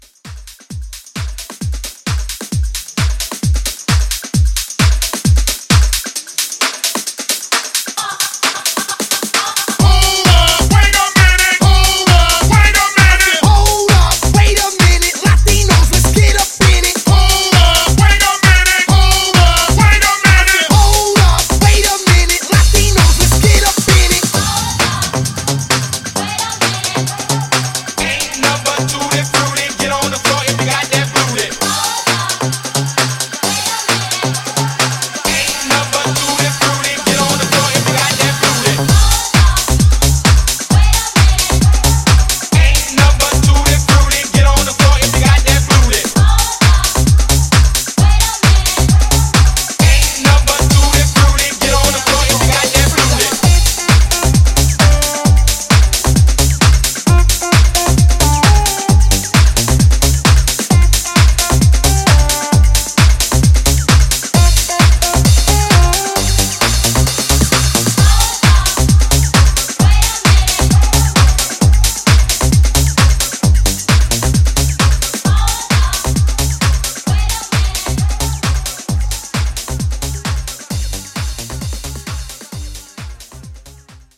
Genres: DANCE , MASHUPS , TOP40
Clean BPM: 126 Time